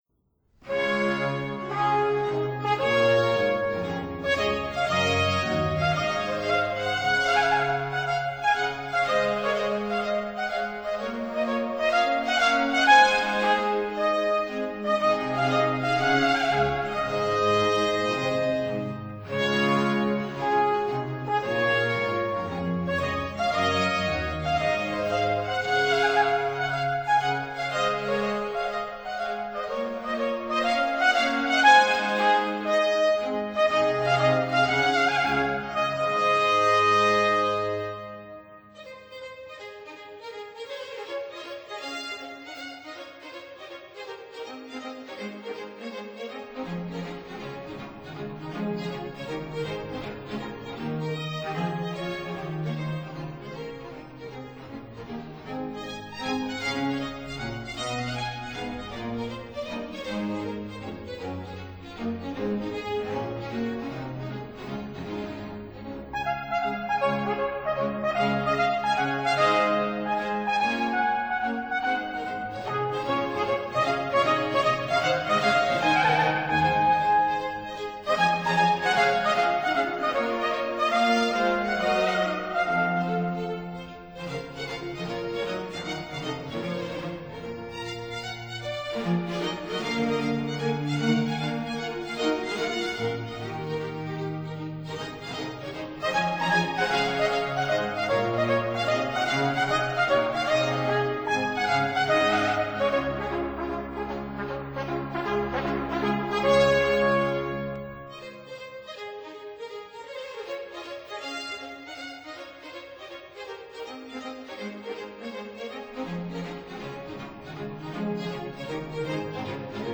Sonatas & Sinfonias
(Period Instruments)